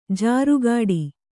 ♪ jāru gāḍi